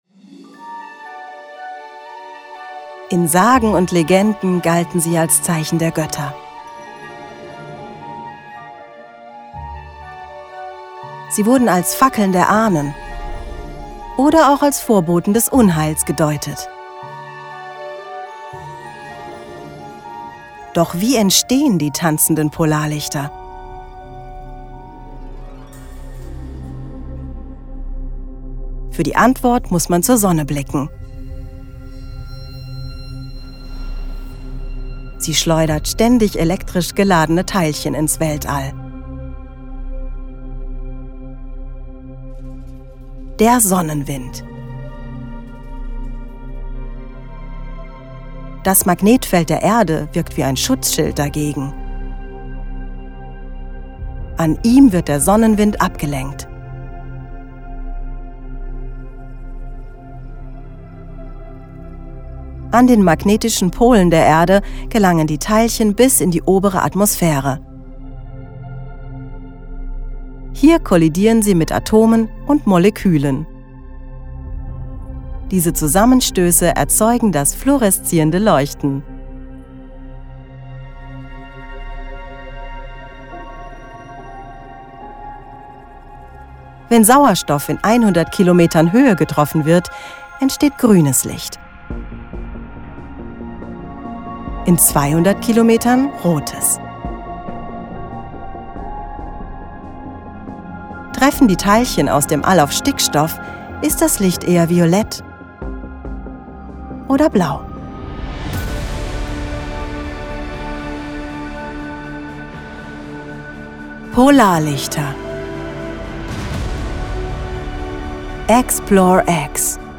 Dokumentation Polarlichter (Demo)
Voice-Over / Dokumentation Dokumentation Polarlichter
Dies ist eine Demo-Aufnahme.
demo-dokumentation-polarlichter.mp3